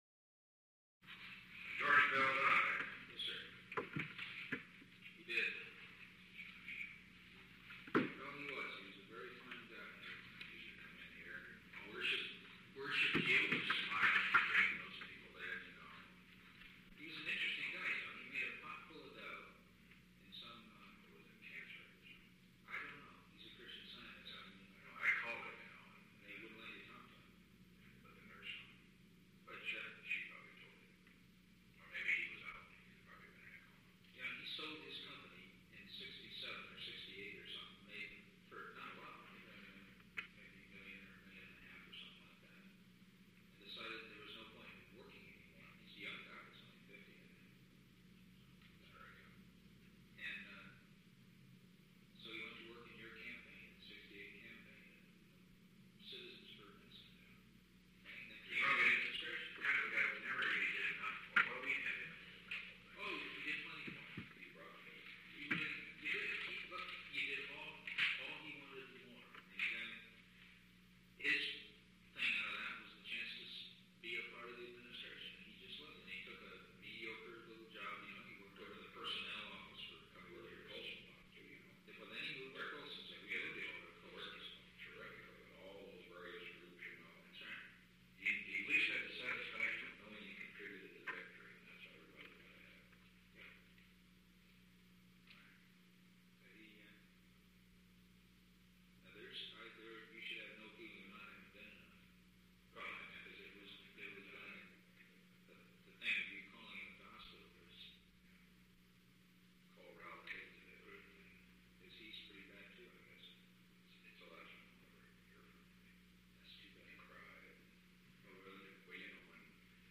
Conversation No. 880-18 Date: March 15, 1973 Time: 4:18 pm-5:30 pm Location: Oval Office The President met with H. R. (“Bob”) Haldeman.
Secret White House Tapes